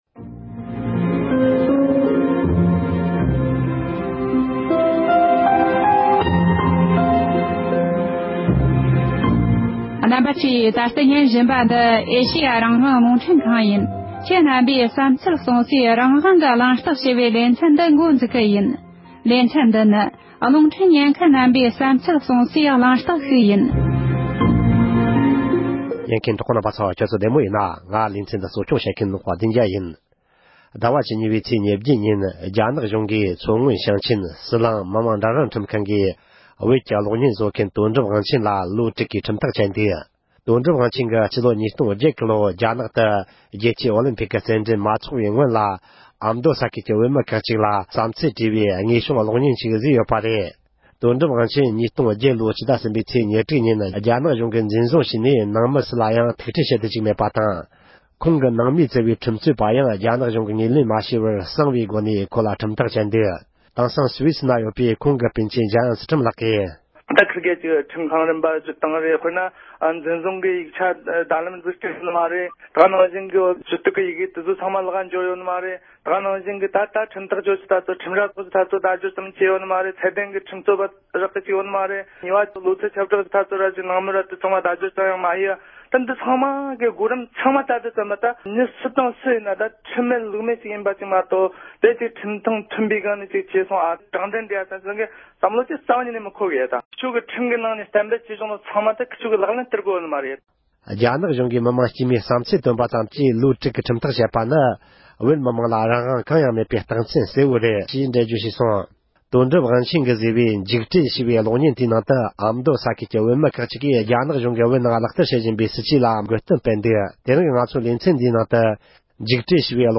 དོན་གྲུབ་དབང་ཆེན་གྱིས་བཟོས་པའི་འཇིགས་བྲལ་ཞེས་པའི་གློག་བརྙན་དེའི་ནང་ཨ་མདོ་ས་ཁུལ་གྱི་བོད་མི་ཁག་ཅིག་གིས་བསམ་ཚུལ་བཤད་ཡོད་པ།